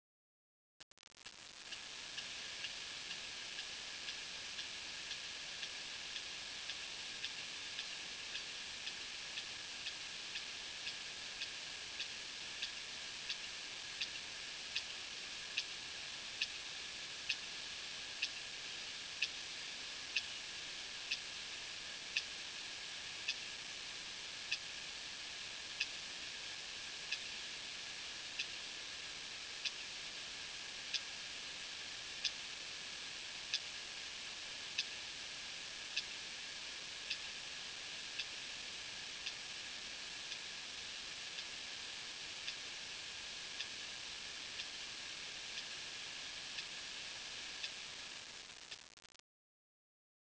Nom commun : Cachalot
Nom latin : Physeter macrocephalus
Ils émettent des clics pour trouver leurs proies et pour communiquer.